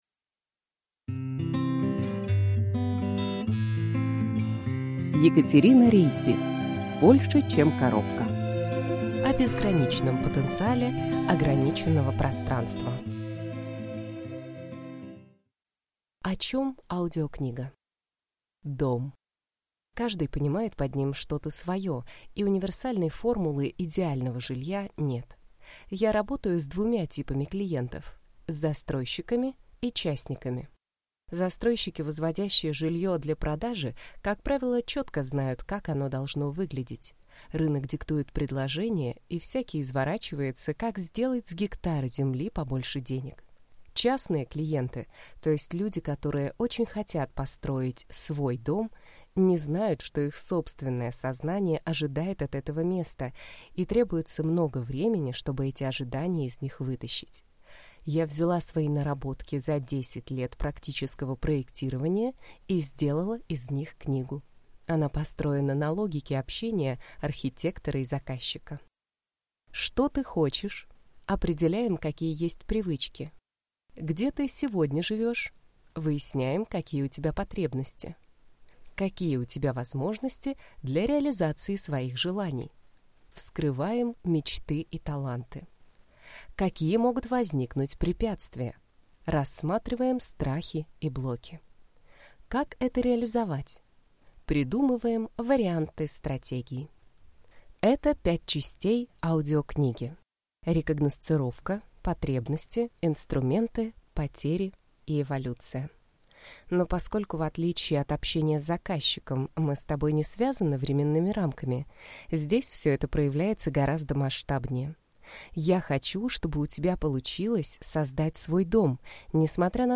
Аудиокнига Больше, чем коробка. О безграничном потенциале ограниченного пространства | Библиотека аудиокниг